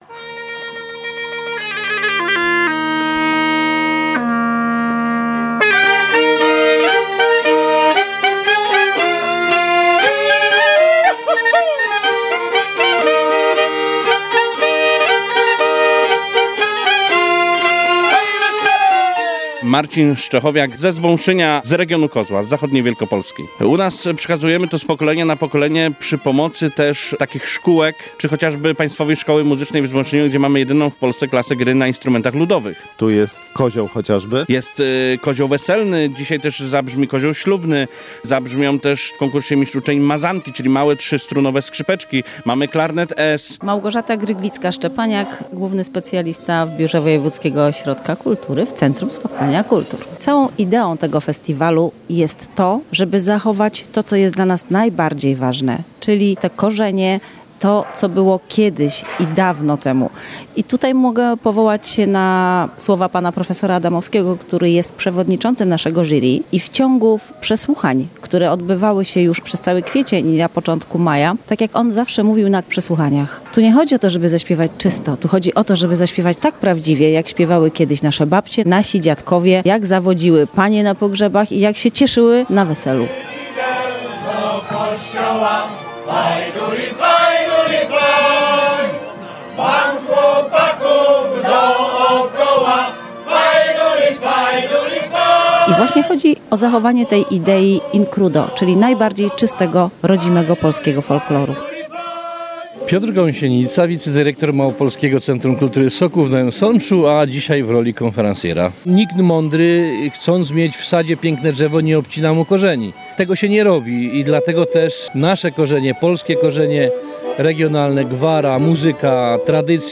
Barwny rozśpiewany korowód rozpoczął dziś (27.06) w Kazimierzu Dolnym Ogólnopolski Festiwal Kapel i Śpiewaków Ludowych.
Nadwiślańskie miasteczko od rana przepełnione jest ludowymi dźwiękami.